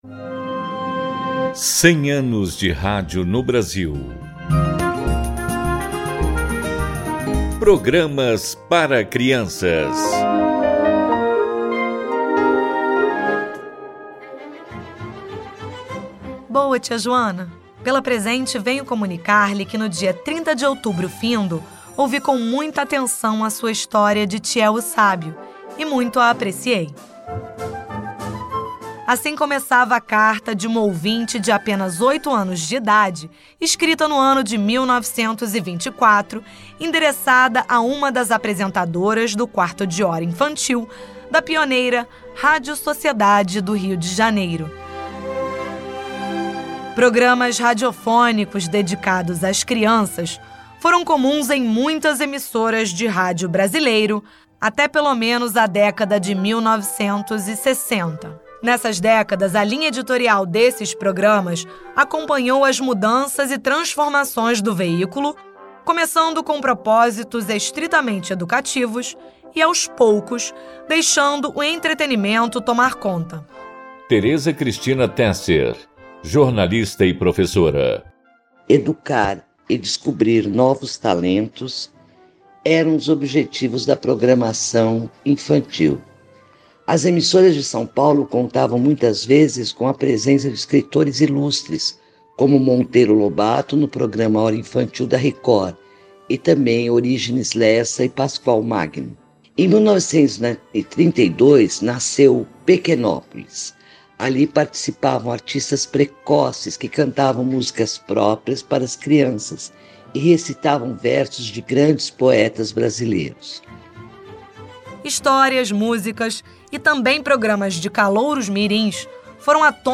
Até 7 de setembro, a Rádio MEC vai produzir e transmitir, diariamente, interprogramas com entrevistas e pesquisas de acervo sobre diversos aspectos históricos relacionados ao veículo.